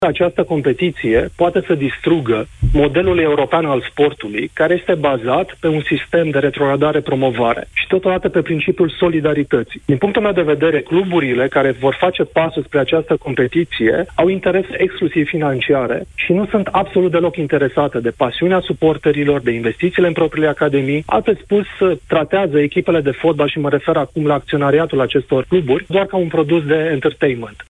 La Europa FM, preşedintele Federaţiei Române de Fotbal, Răzvan Burleanu, a criticat la rându-i iniţiativa: